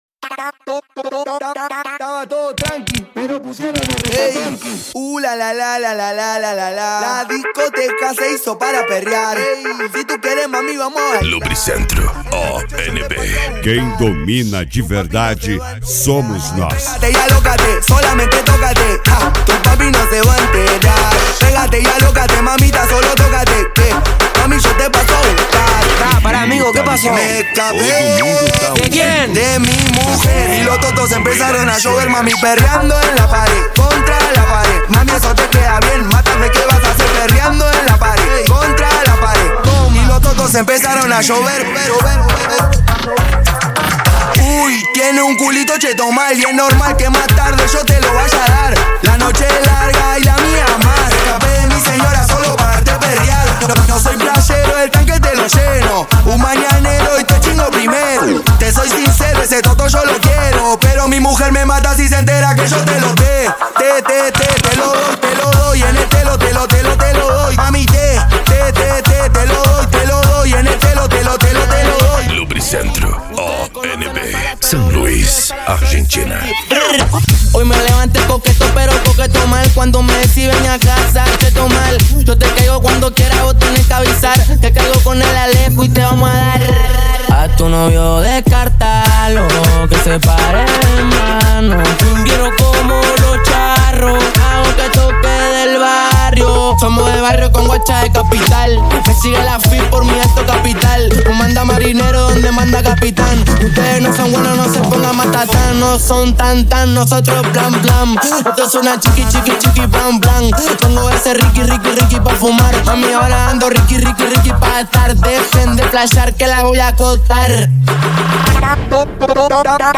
Cumbia
Funk
Remix